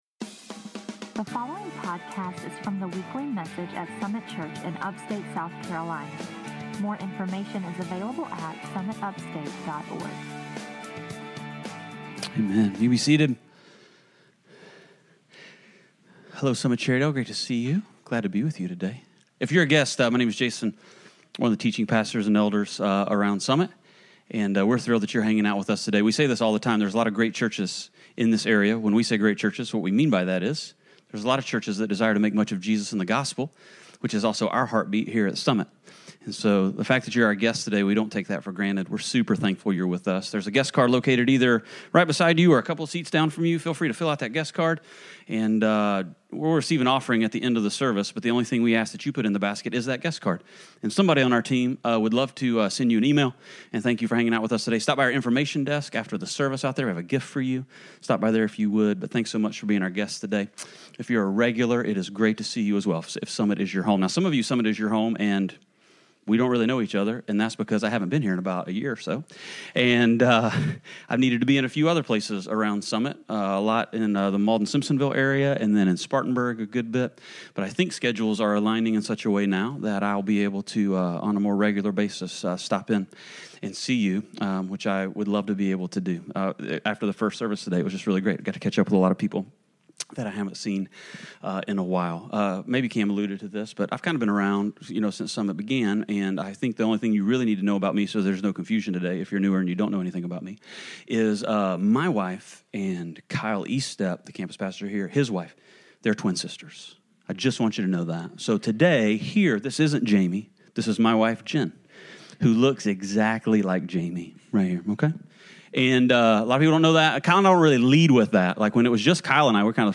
1 Peter 3; Part 2 (recorded at Cherrydale)